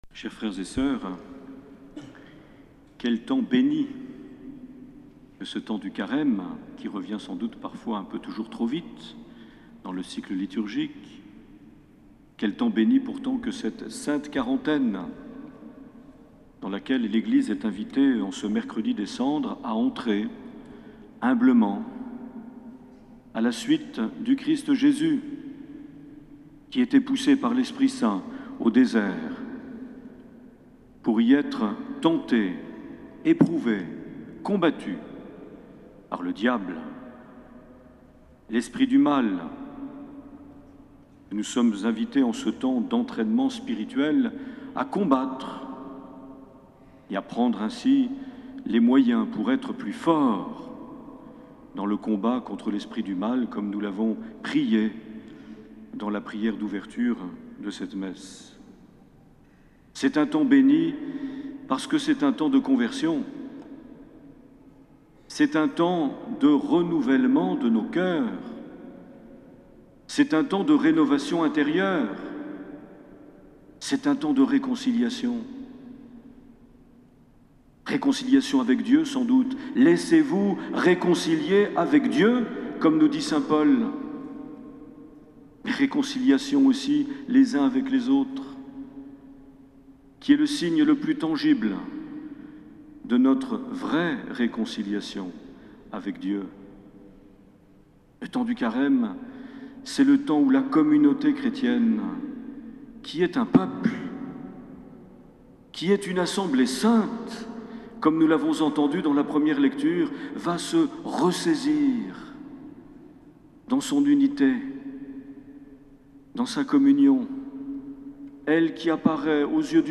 17 février 2010 - Cathédrale de Bayonne - Mercredi des Cendres
Une émission présentée par Monseigneur Marc Aillet